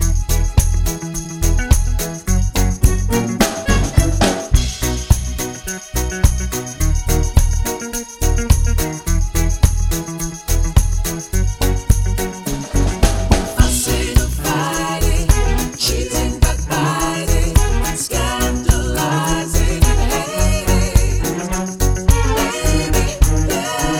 Three Semitones Down Reggae 3:16 Buy £1.50